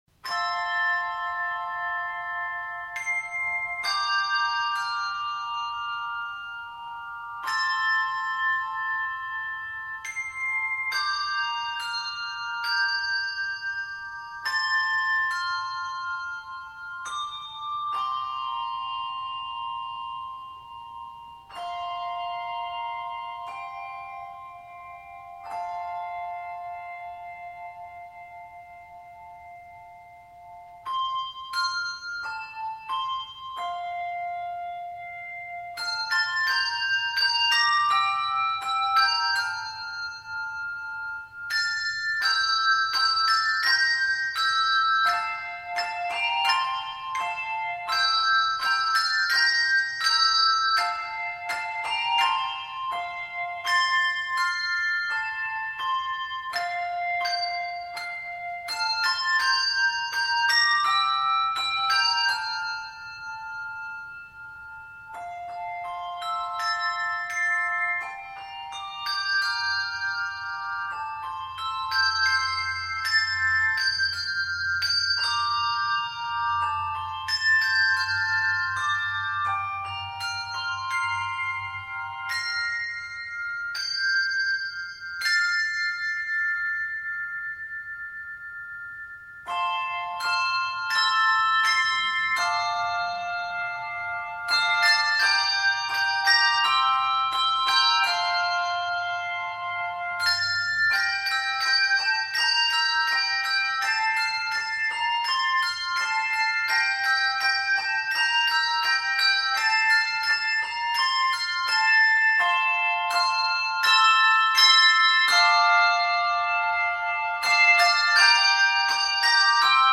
12-bell arrangement
Reproducible download. 49 measures; key of F Major.